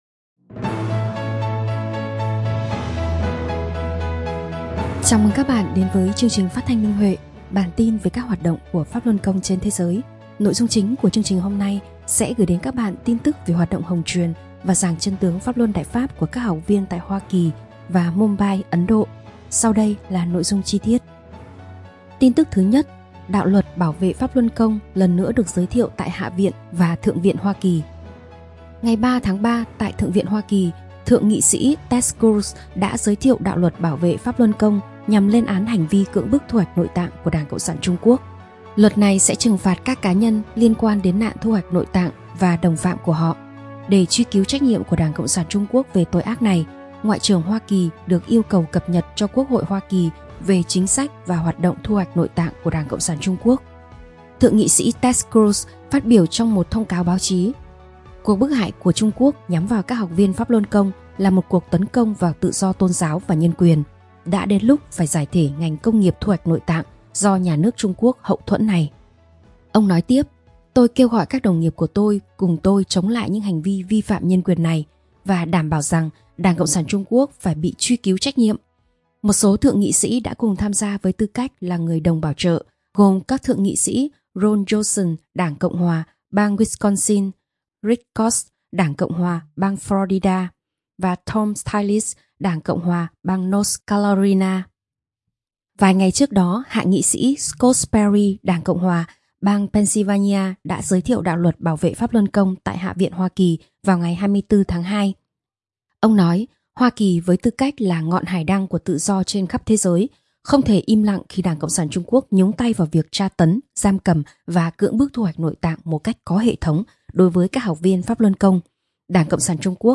Chương trình phát thanh số 293: Tin tức Pháp Luân Đại Pháp trên thế giới – Ngày 7/3/2025